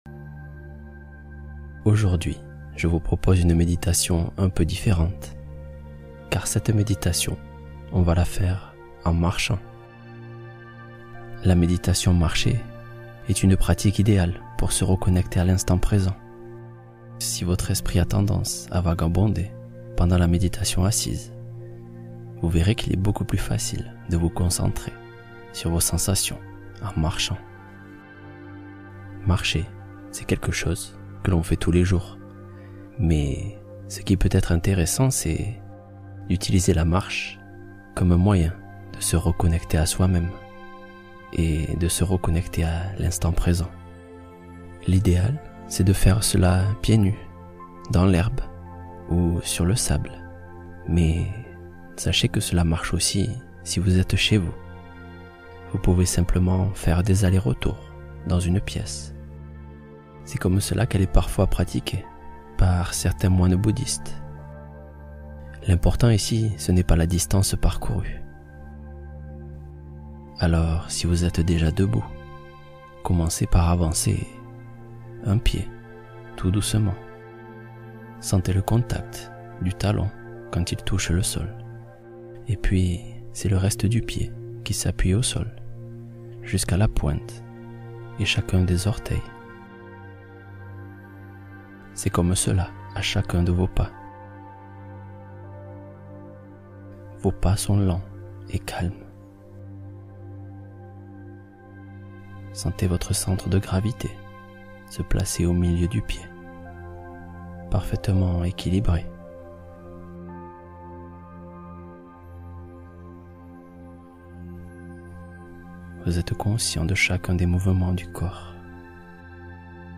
S’aimer pleinement — Méditation guidée pour cultiver la bienveillance envers soi